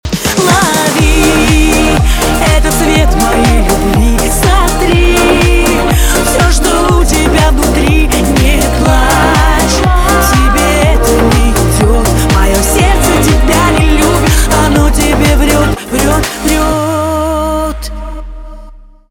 поп
грустные , печальные
битовые , басы